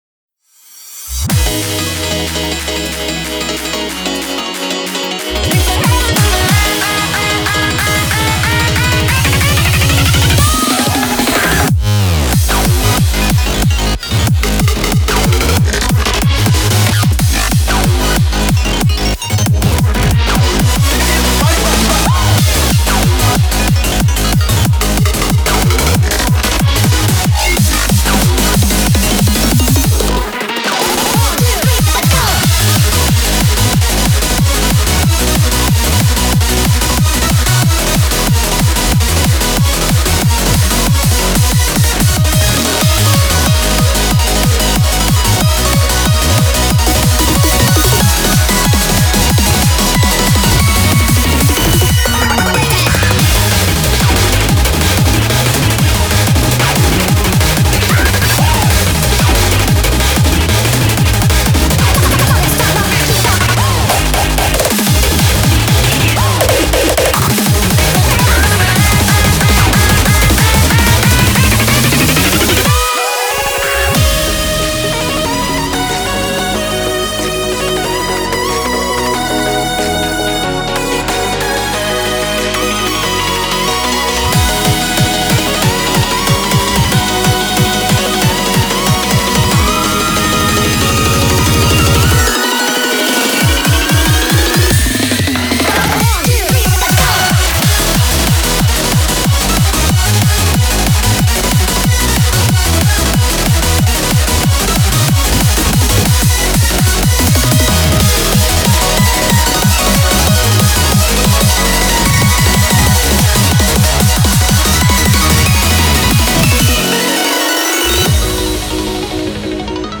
BPM185